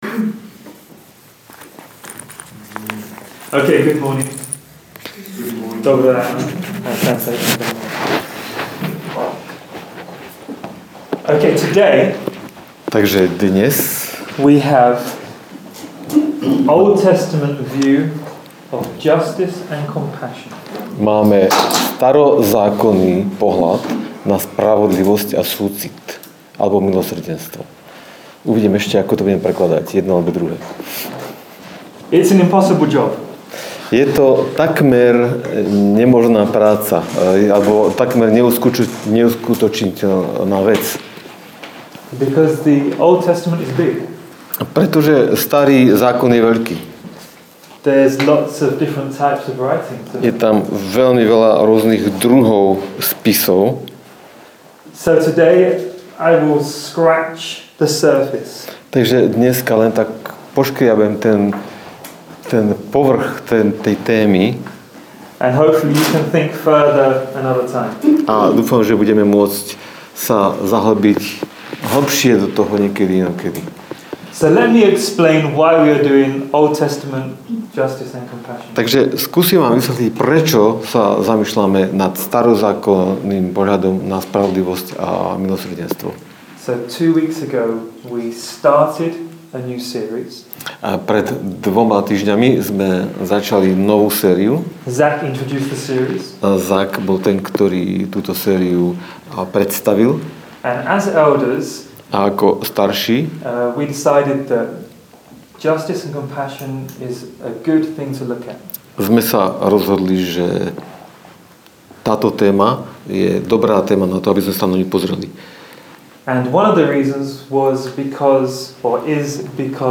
Nahrávka kázne Kresťanského centra Nový začiatok z 21. apríla 2013